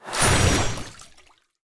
sfx_skill 05_1.wav